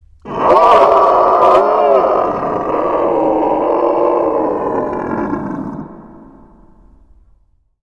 Cartoon Cat Sounds
• Quality: High
Cartoon Cat - Variant 2 (growl)206.35 kB192kB7